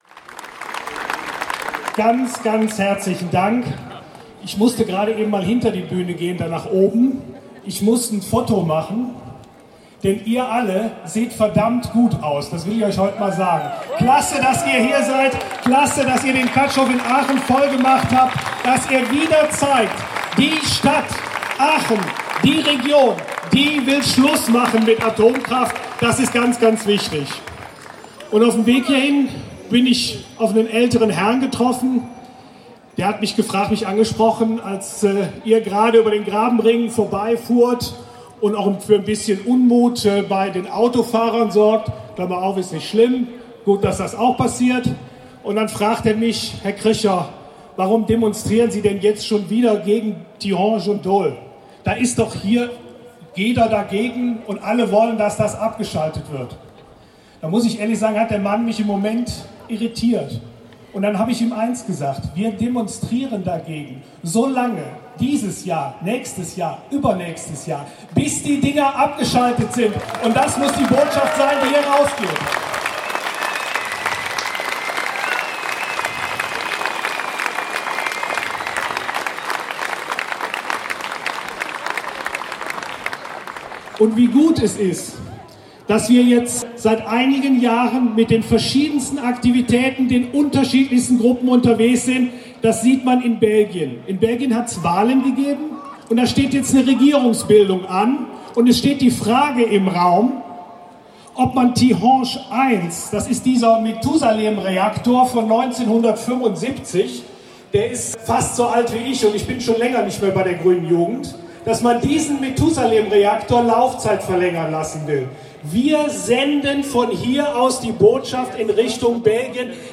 Kapitel 3: Abschlusskundgebung